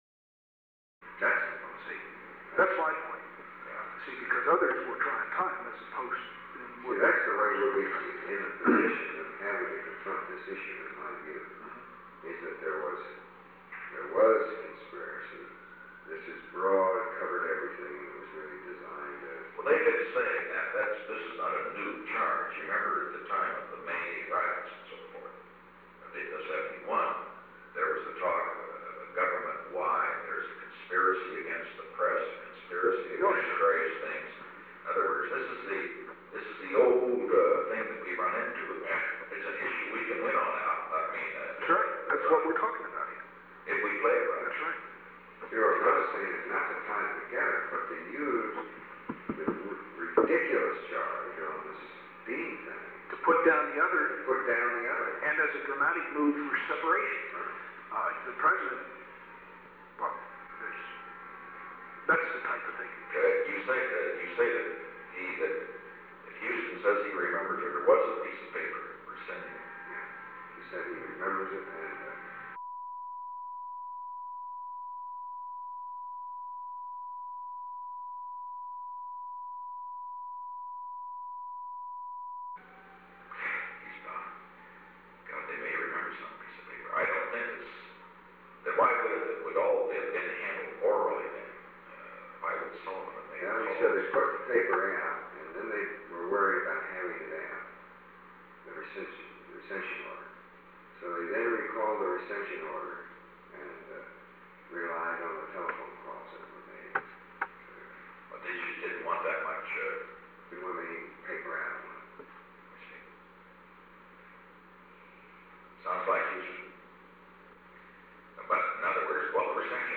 Conversation No. 921-21 Date: May 17, 1973 Time: 12:45 pm - 1:25 pm Location: Oval Office The President met with an unknown man.
Secret White House Tapes | Richard M. Nixon Presidency